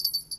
Single Bell 2
bells ding hit jingle sound effect free sound royalty free Voices